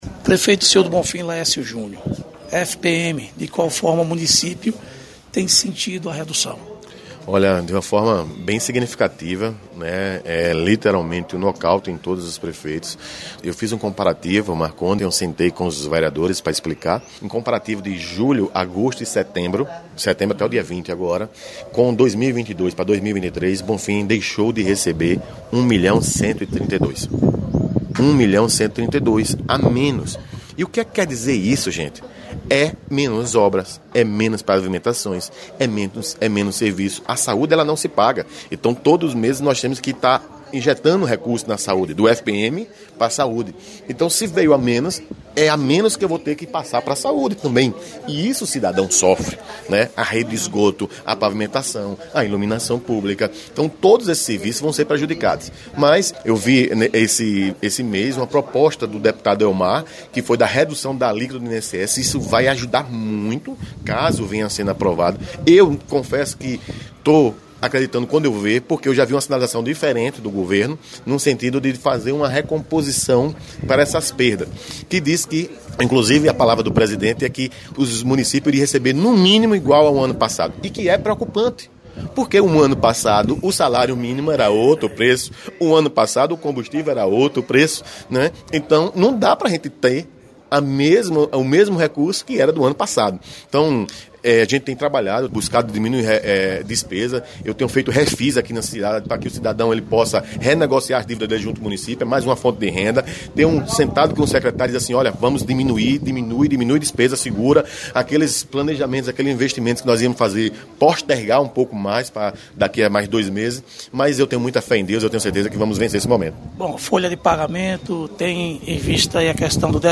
Prefeito de Sr. do Bonfim Laécio Junior, falando sobre a redução do FPM